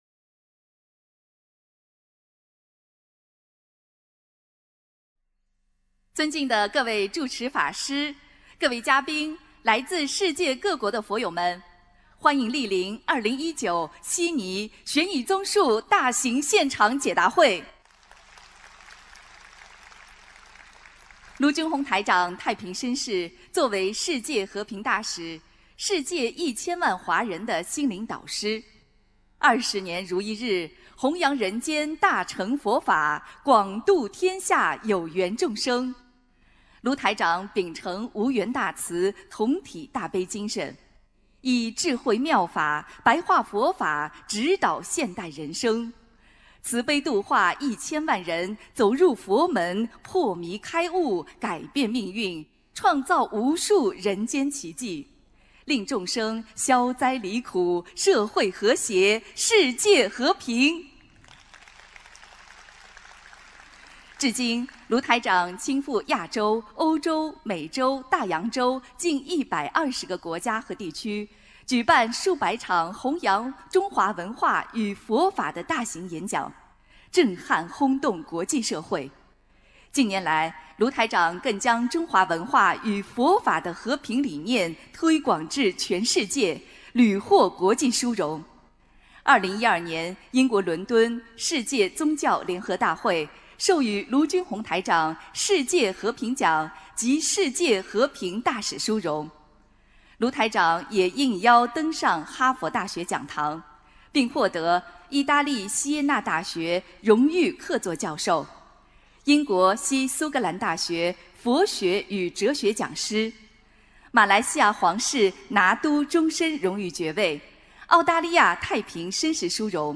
2019年1月27日澳大利亚悉尼法会（视音文图） - 2019-2020年 - 心如菩提 - Powered by Discuz!